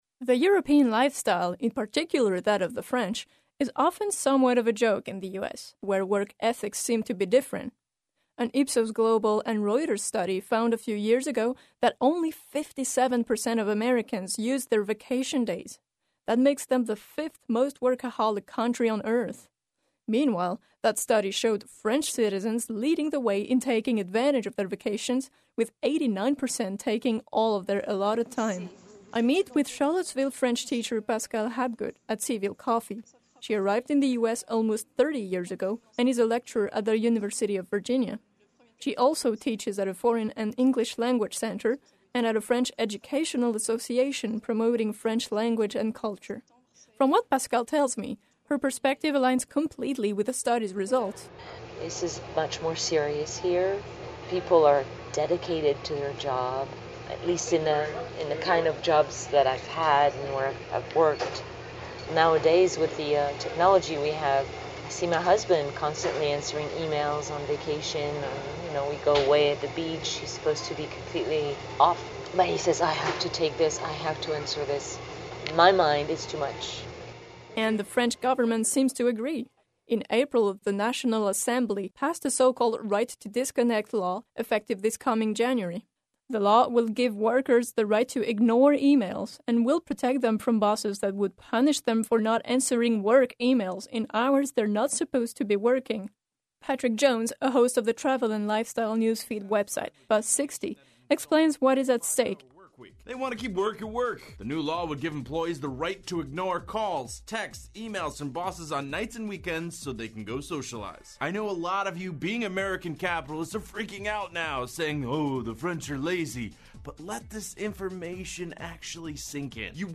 [French conversation at C’Ville Coffee]